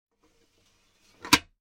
Звук закрытия солнцезащитного стекла шлема от прямых солнечных лучей